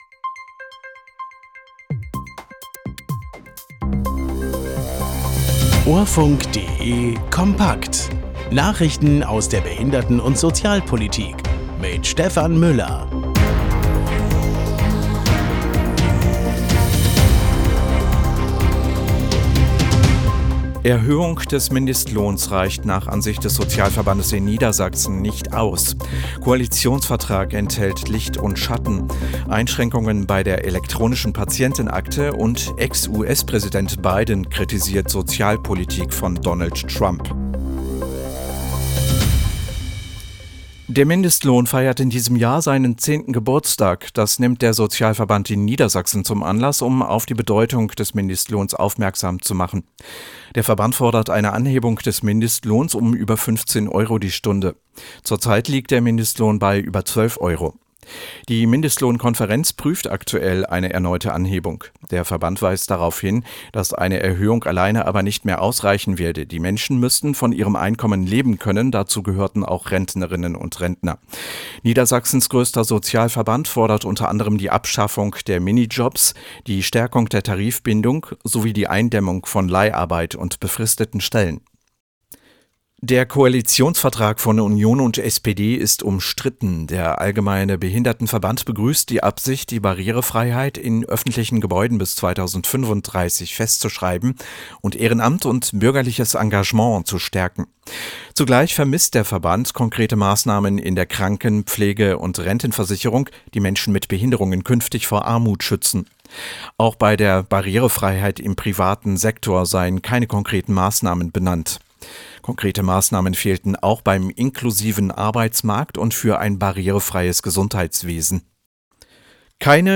Nachrichten aus der Behinderten- und Sozialpolitik vom 23.04.2025